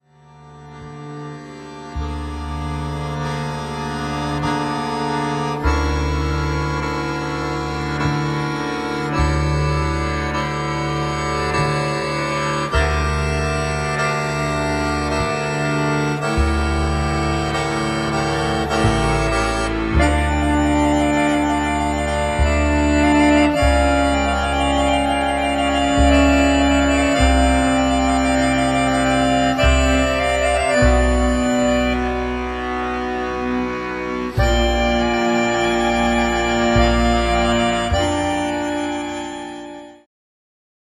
akordeon, gajda, kaval
klarnet, tambura
kontrabas
instrumenty perkusyjne
śpiew